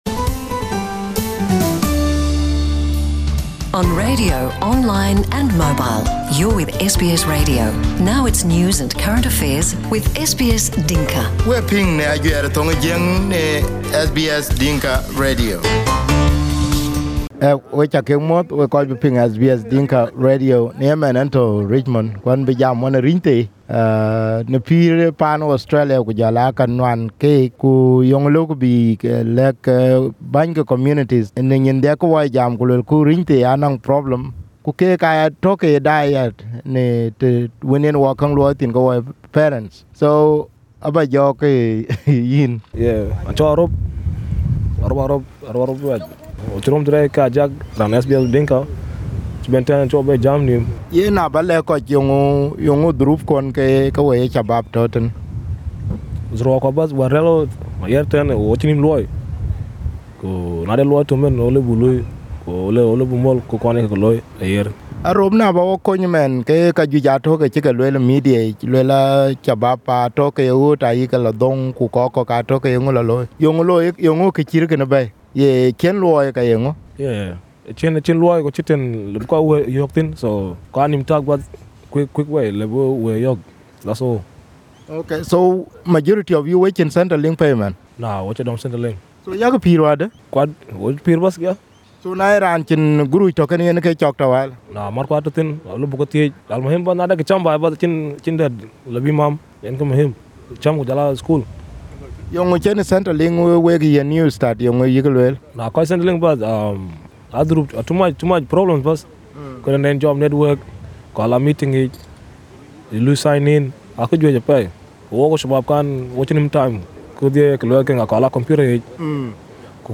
These youth have been to the justice system, and that makes it hard for many of them to find employment or even courses that could help them find better jobs. In this podcast, you will be listening to three youths who were delegated to tell their stories.